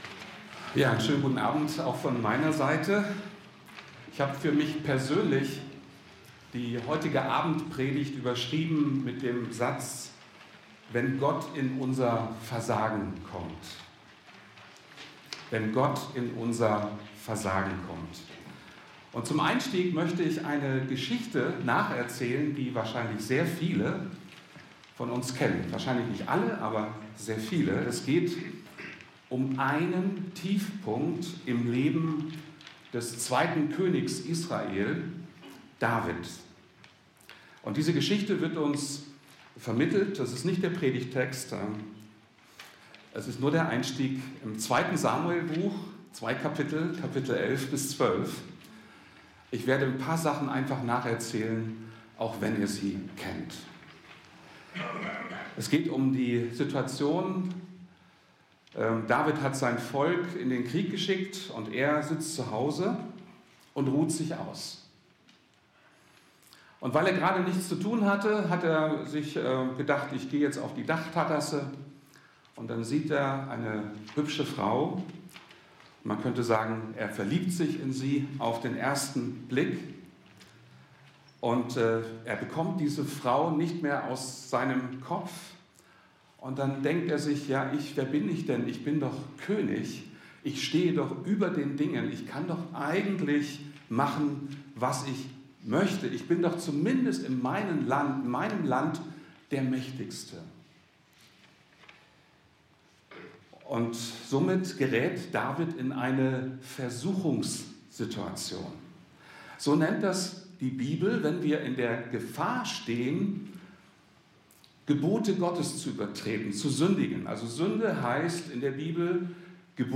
Sprüche 28, 13 - Wenn Gott In Unser Versagen Kommt FeG München Mitte Predigt podcast To give you the best possible experience, this site uses cookies.